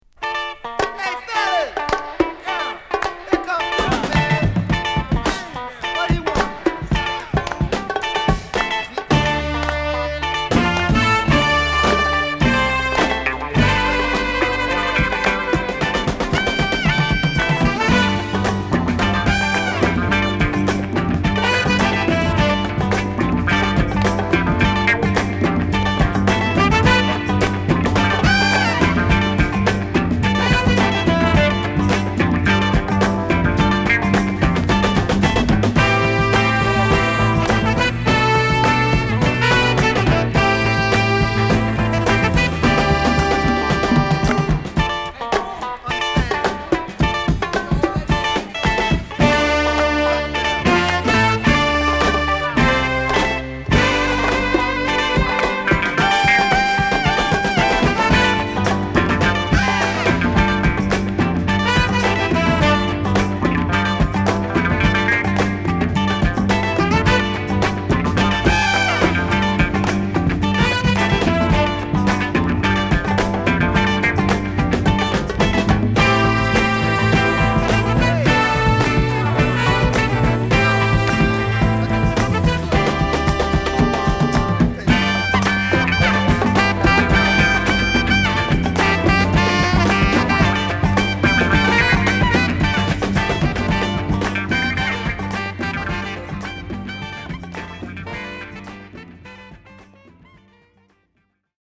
ドラムの打ちっぷりにファンキーなベースにギターの刻み、熱いホーンが絡むファンク・クラシック！！